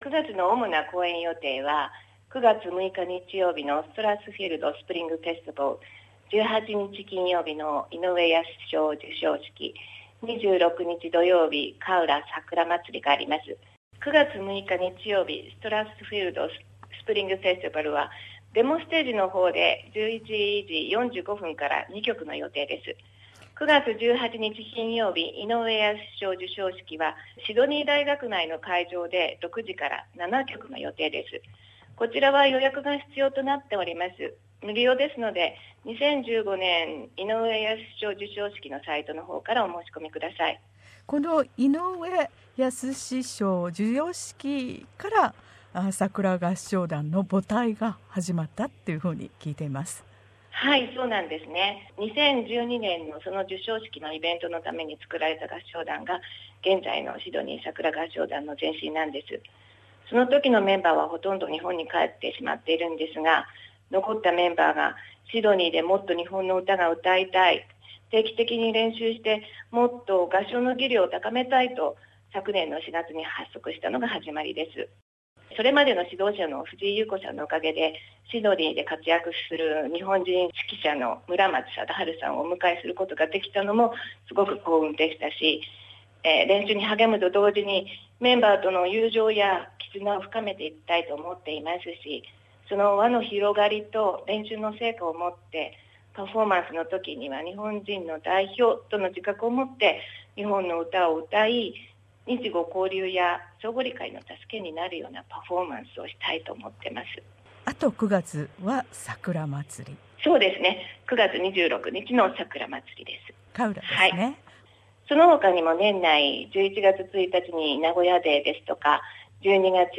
さくら合唱団は、シドニー在住日本人の合唱団。2014年の結成以来、レパートリーも公演も増えて活躍中。